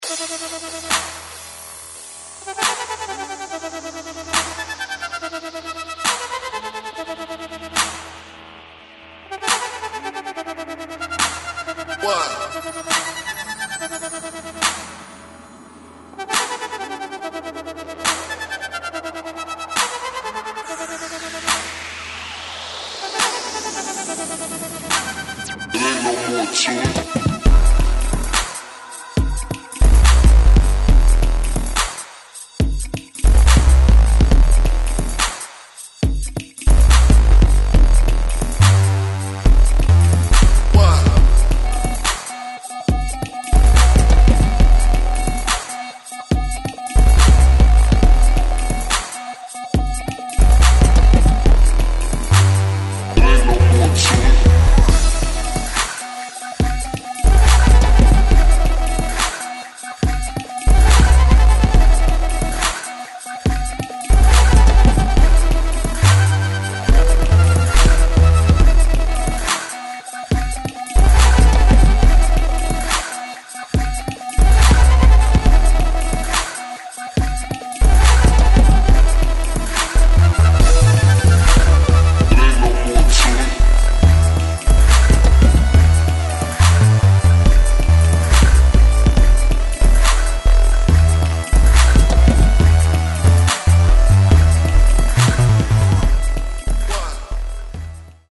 Styl: Dub/Dubstep